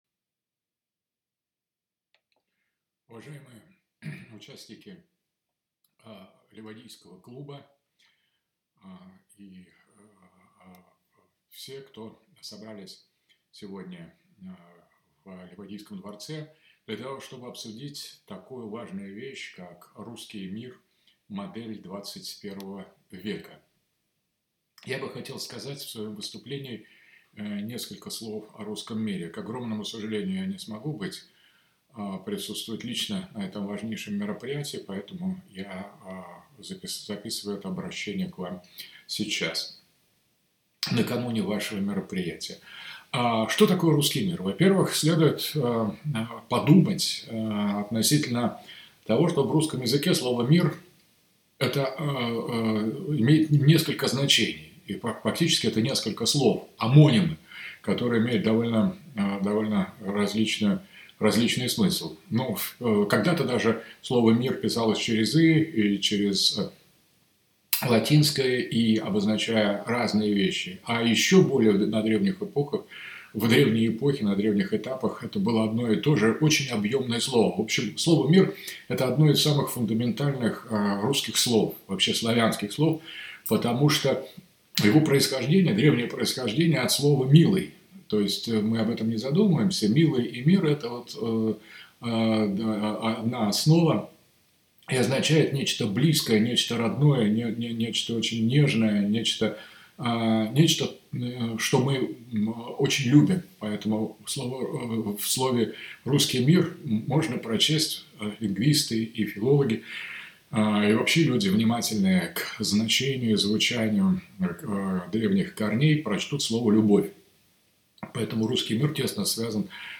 Выступление Александра Дугина на XVII фестивале "Великое русское слово"
Выступление прошла в рамках Левадийского клуба.